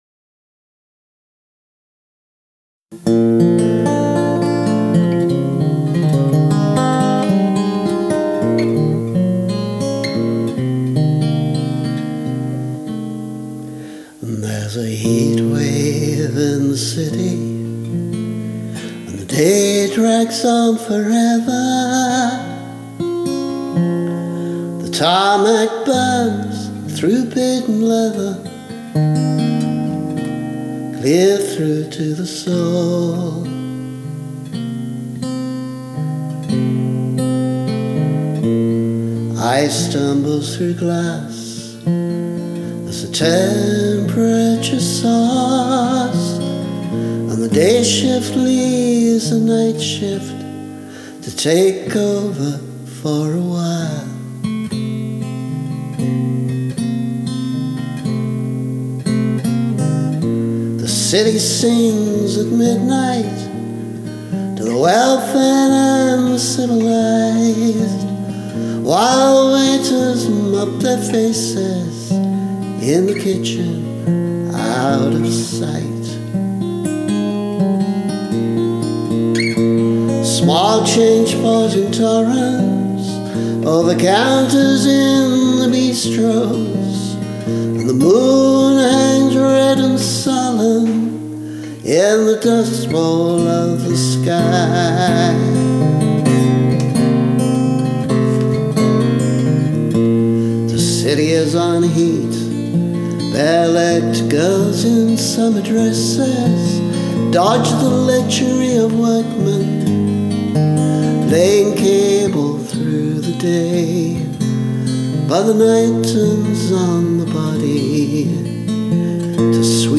*I omit these lines when I sing this song now. In fact, here’s a slightly rough 2020 demo version: